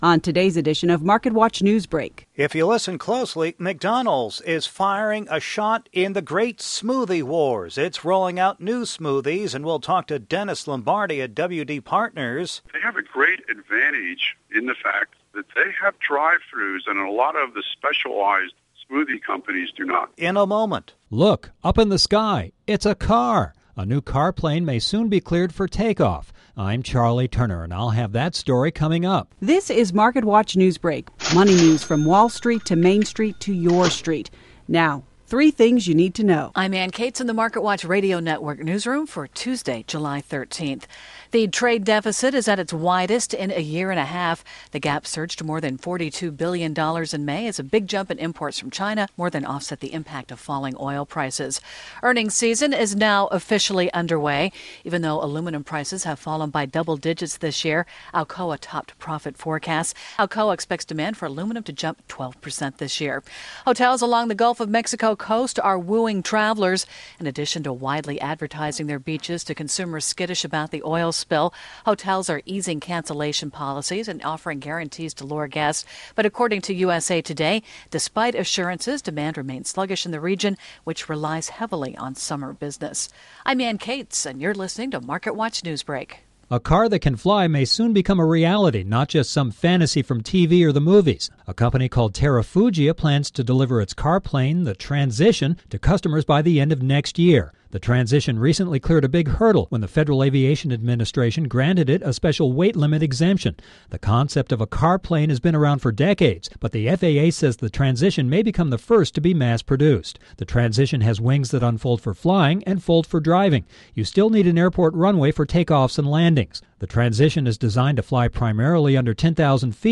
I had an interesting interview on